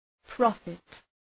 Προφορά
{‘prɒfıt}